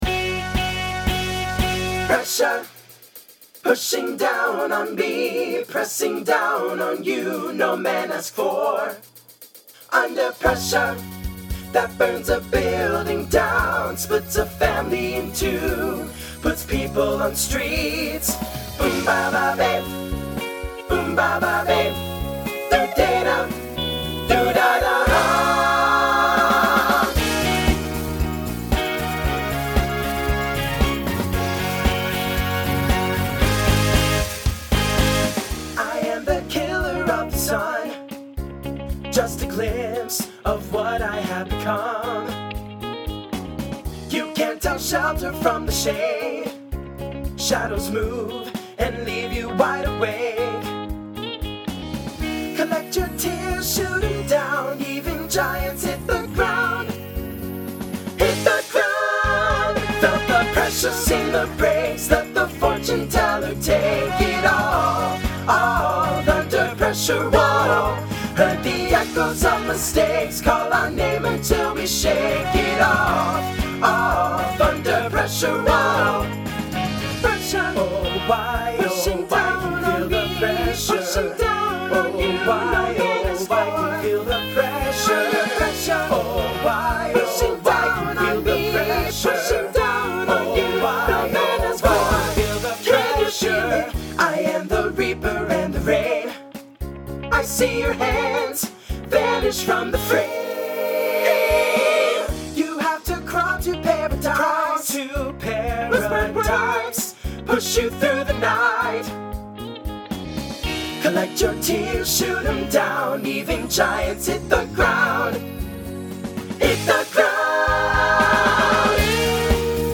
Voicing SATB Instrumental combo Genre Rock
2000s Show Function Mid-tempo